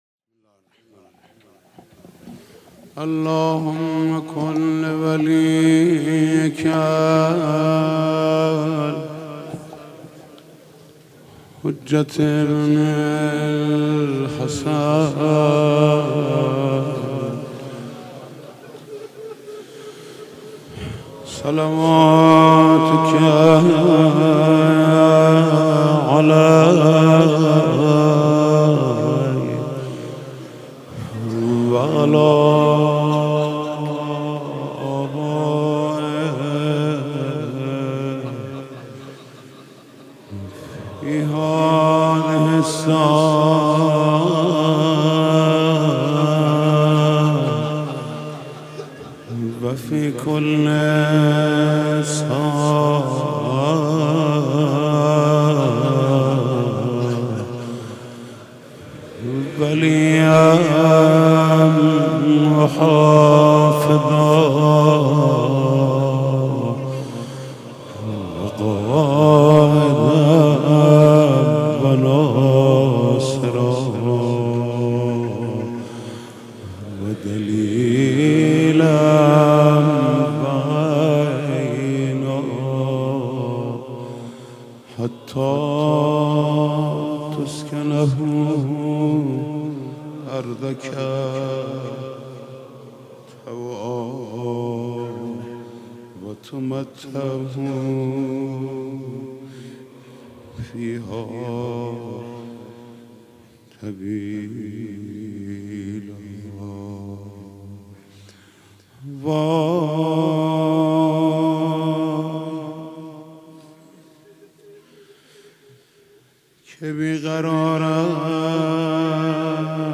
شب اول فاطمیه اول 1436 | هیات رایه العباس | حاج محمود کریمی
وای چه بی قرارم | مناجات با امام زمان